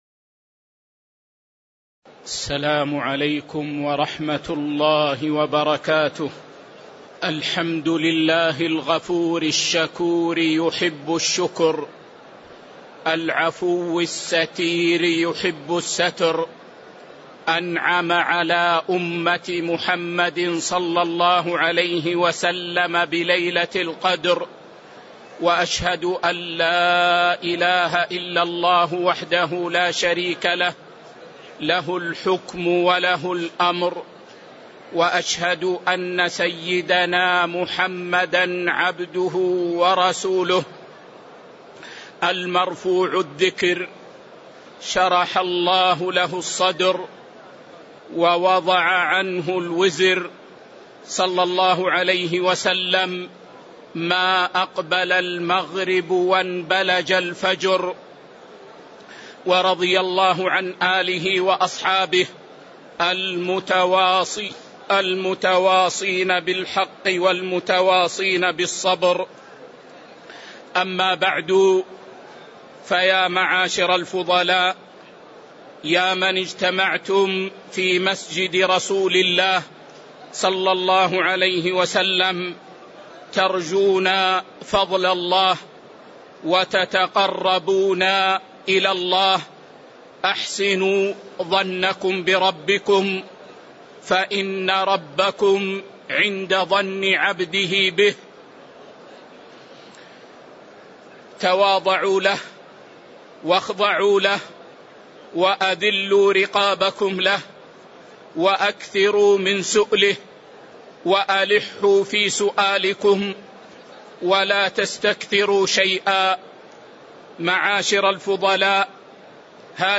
تاريخ النشر ٢٨ رمضان ١٤٤٣ هـ المكان: المسجد النبوي الشيخ